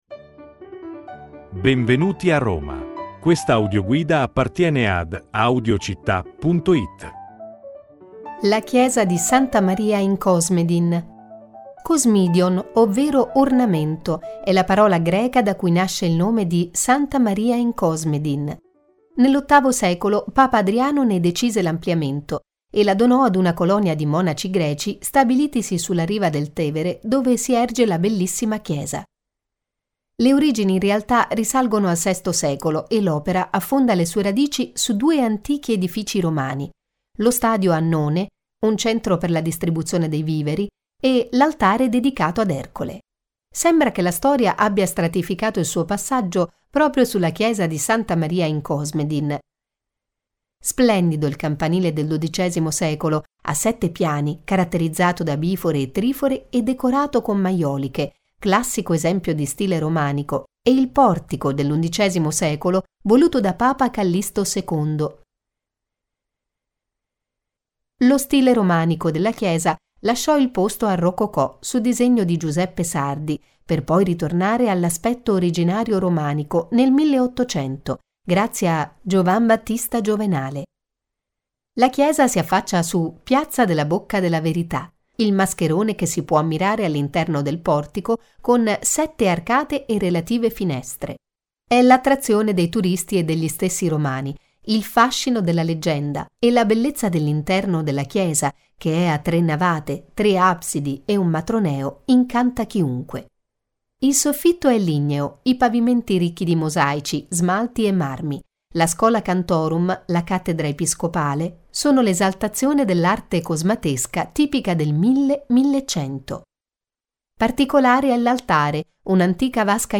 Audioguida Roma - Santa Maria in Cosmedin; Kosmidiòn, ovvero ornamento, è la parola greca da cui prende il nome di santa Maria in Cosmedin.